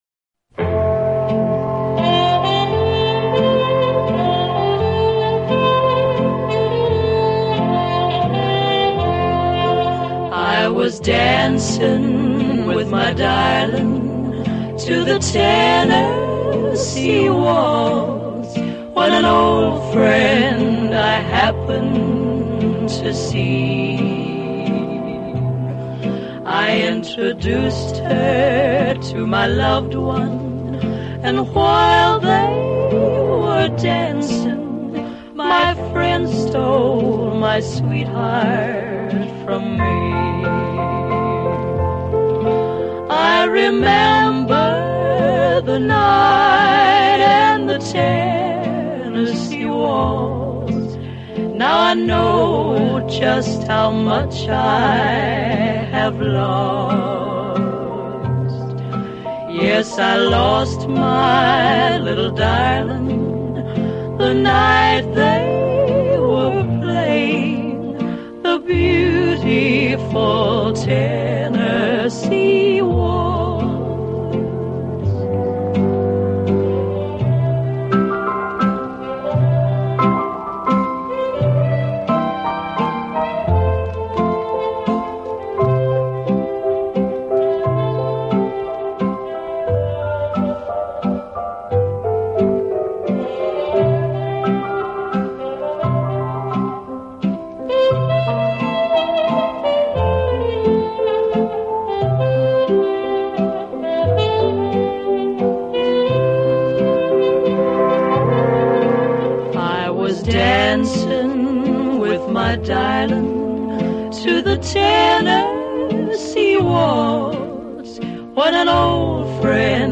【浪漫纯音乐】
浪漫的国度、浪漫的音乐、浪漫的演绎、浪漫的情怀……